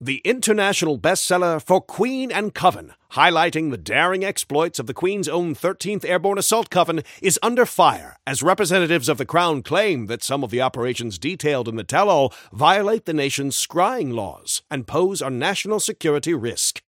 Newscaster_headline_81.mp3